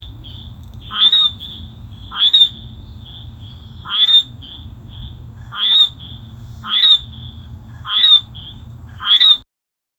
a-frog-croaking-4opjgn5q.wav